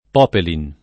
vai all'elenco alfabetico delle voci ingrandisci il carattere 100% rimpicciolisci il carattere stampa invia tramite posta elettronica codividi su Facebook popeline [fr. popl & n ] s. f. — adatt. in it. come popelin [ p 0 pelin ] s. m., più raramente come popelina [ popel & na ] s. f.